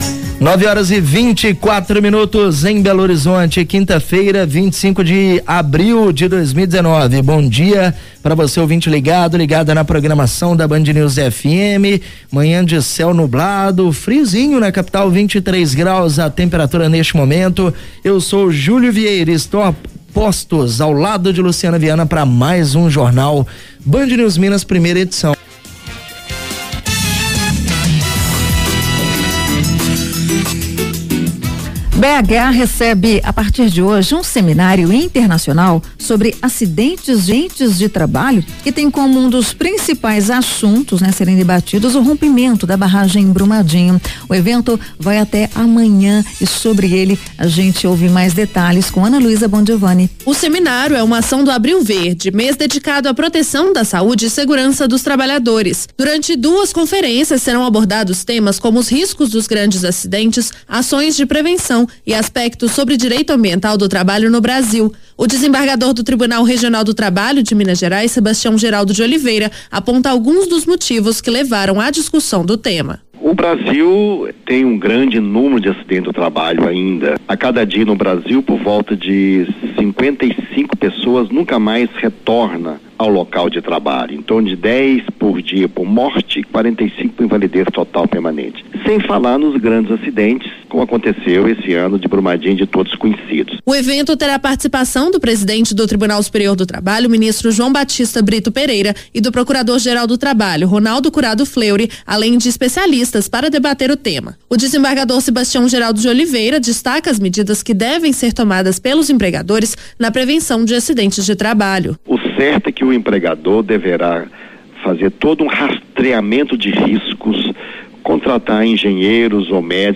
Na manhã desta quinta-feira (25), o gestor nacional do Programa Trabalho Seguro, desembargador do TRT-MG Sebastião Geraldo, deu uma entrevista para a rádio Band News em que falou sobre o início do seminário e as medidas que devem ser tomadas por empregadores para a prevenção de a acidentes.
Gravacao_Band_News_Semin_edit2.mp3